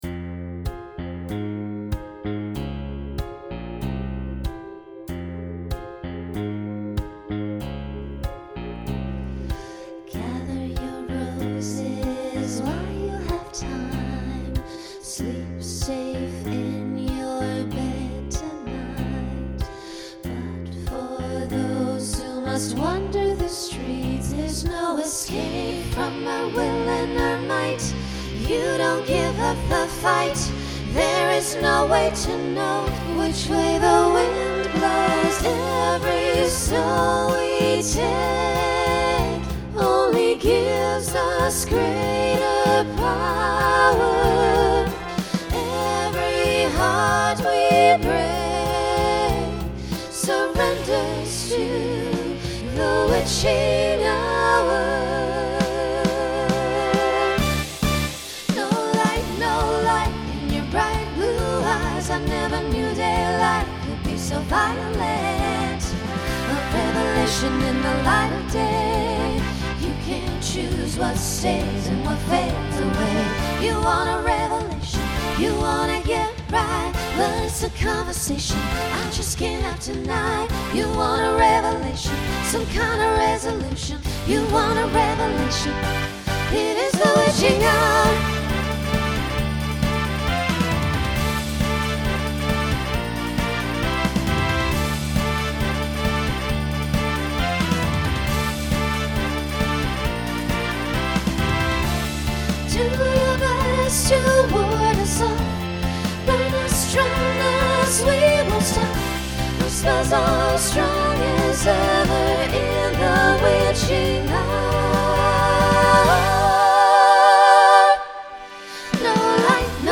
Genre Rock Instrumental combo
Story/Theme Voicing SSA